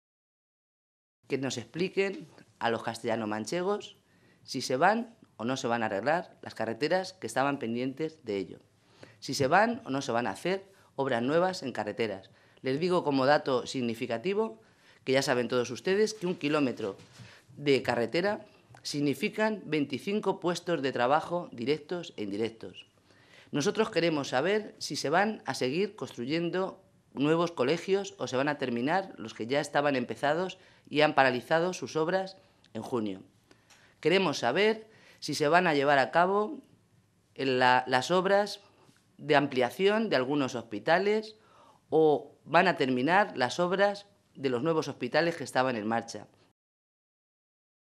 Rosa Melchor, diputada regional del PSOE de Castilla-La Mancha
Cortes de audio de la rueda de prensa